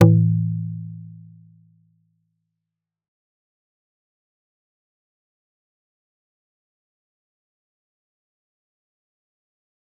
G_Kalimba-A2-f.wav